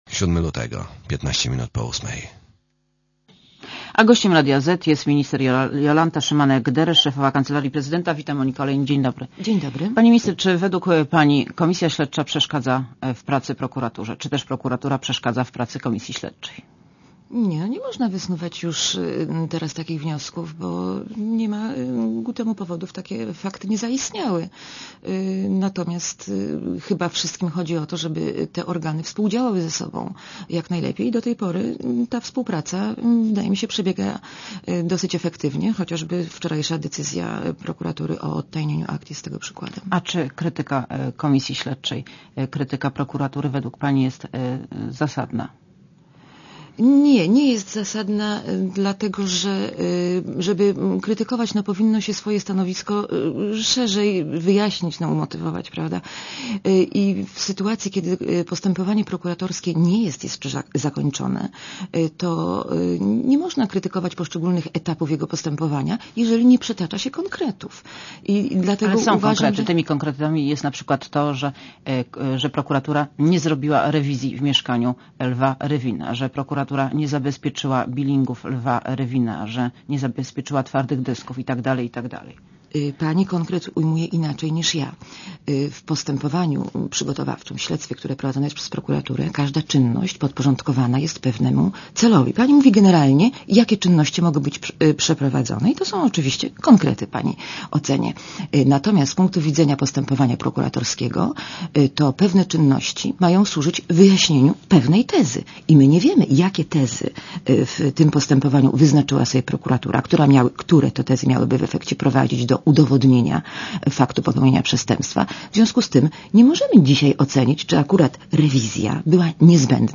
Monika Olejnik rozmawia z Jolantą Szymanek-Deresz szefową Kancelarii Prezydenta